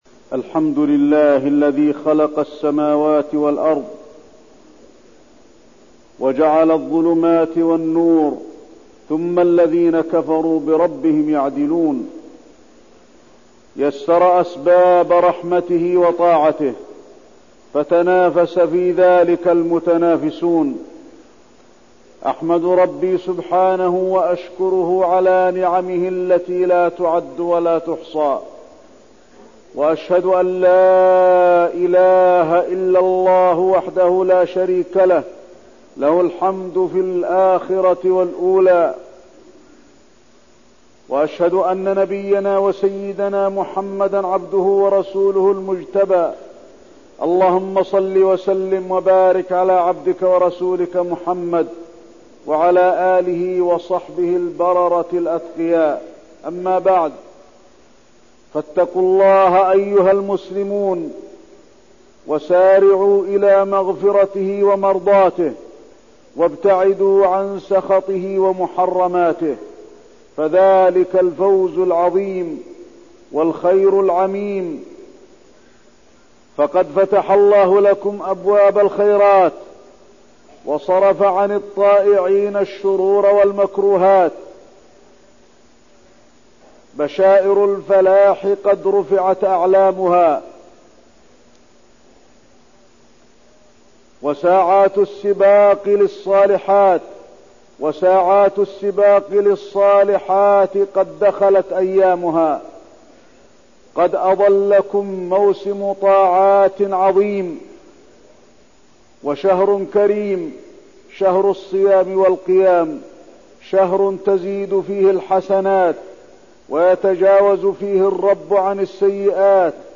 تاريخ النشر ٢٨ شعبان ١٤١٣ هـ المكان: المسجد النبوي الشيخ: فضيلة الشيخ د. علي بن عبدالرحمن الحذيفي فضيلة الشيخ د. علي بن عبدالرحمن الحذيفي استقبال شهر رمضان